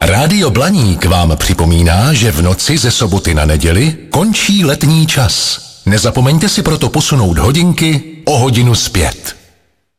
Některá nicméně do vysílání pouští speciální jingly.